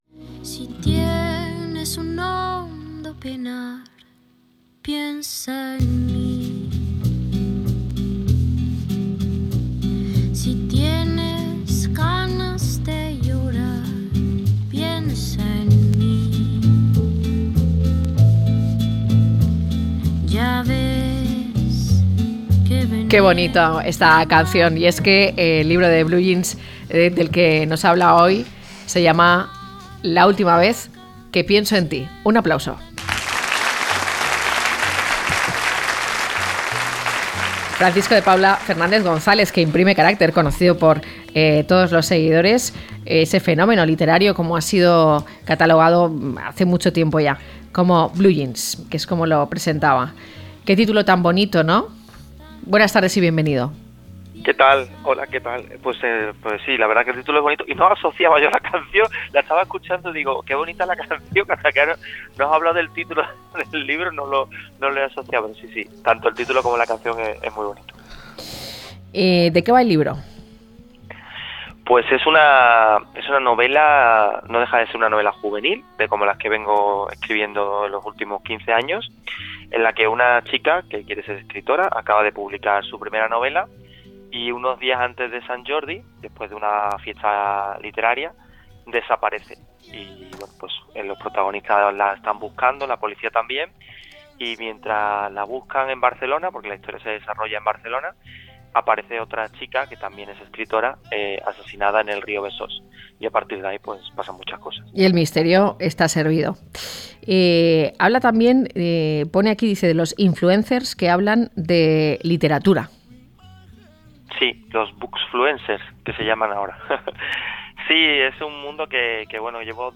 Blue Jeans nos presenta La última vez que pienso en ti Hablamos con el escritor Francisco de Paula Fernández González, el fenómeno literario conocido como Blue Jeans quien presenta La última vez que pienso en ti, una novela trepidante y adictiva, en la que Blue Jeans traslada al lector al mundo de los libros, de las editoriales, de los influencers que hablan de literatura y de los jóvenes que desean ser escritores, con el amor, la investigación y el misterio como ingredientes principales.